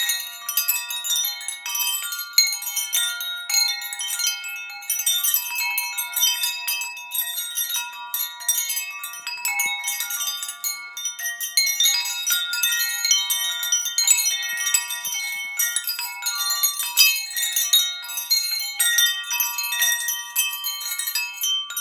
Větrná zvonkohra 82cm
Větrná zvonkohra je složena z dřevěných částí, kovových trubiček různých délek a čínských mincí štěstí, které se při každém závanu větru rozezní jemným zvukem připomínajícím doslova andělské zvonění.
Materiál: kov, dřevo